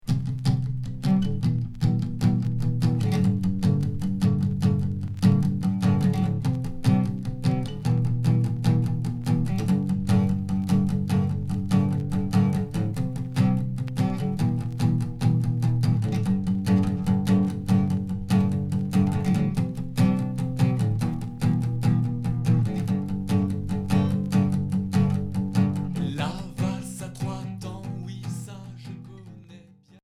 Pop indé